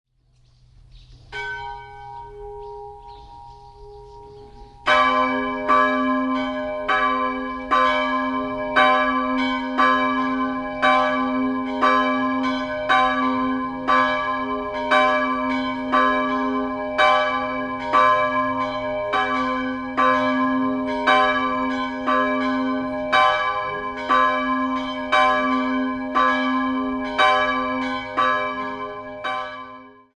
Die "große" Glocke c'' stammt aus dem alten Gussstahlgeläute der Dietfurter Stadtpfarrkirche und wurde 1947 in Bochum gegossen. Die kleine Marienglocke goss Johann Gordian Schelchshorn in Regensburg im Jahr 1694.